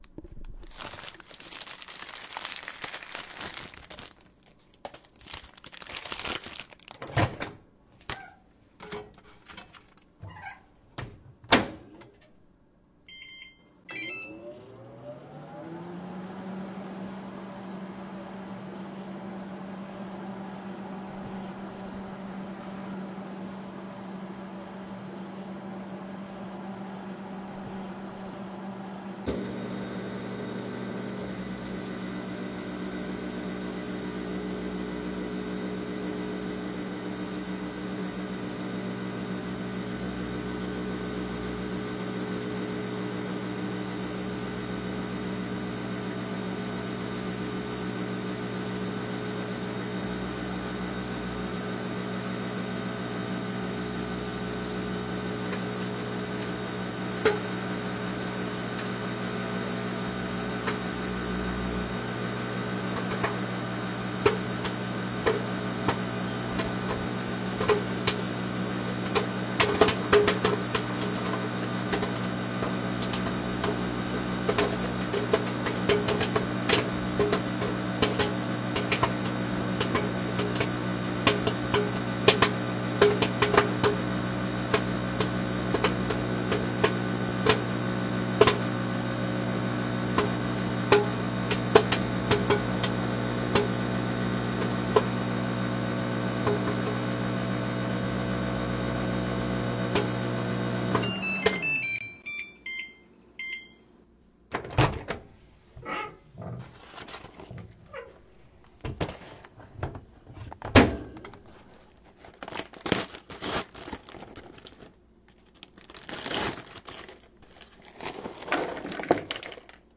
微波炉爆米花的制作
描述：记录了我在微波炉中爆出一袋迷你的爆米花。记录了打开袋子、放入微波炉、微波炉启动、爆米花爆开、结束、打开微波炉、打开爆米花袋子、把爆米花倒在碗里的声音。用H2 Zoom